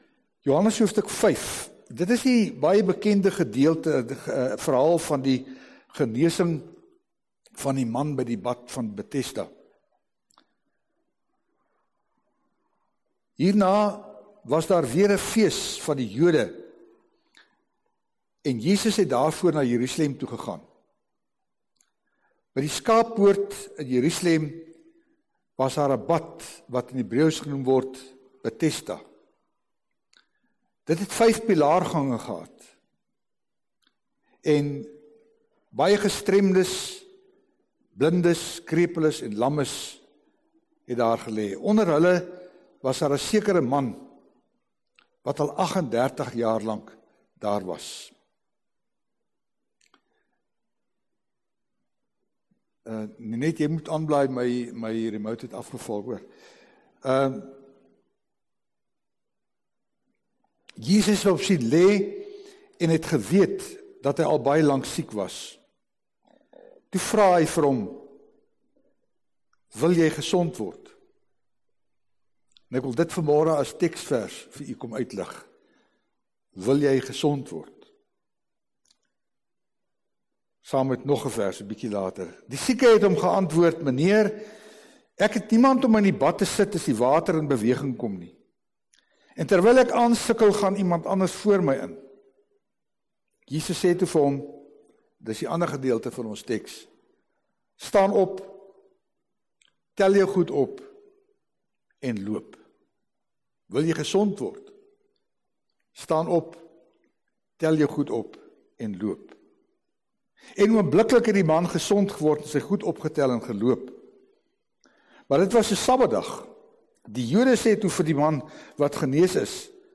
Erediens - 9 Augustus 2020